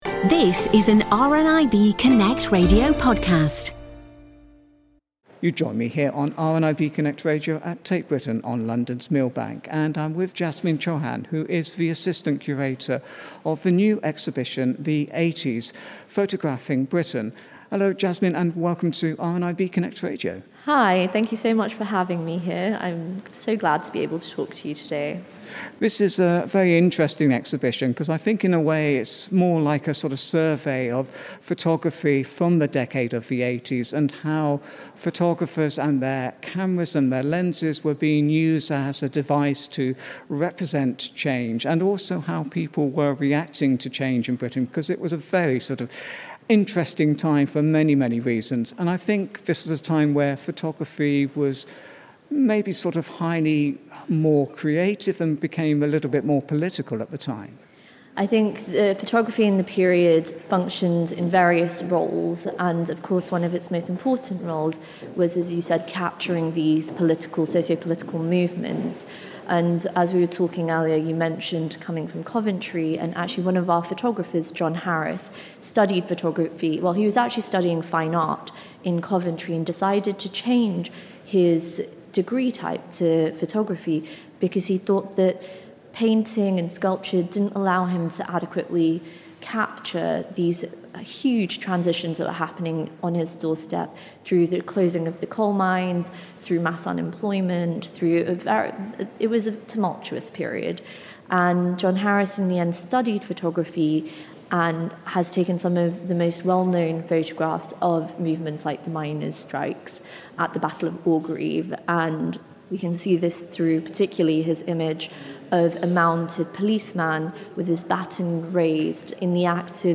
During the Press View of ‘The 80s: Photographing Britain’ on Tuesday 19 November 2024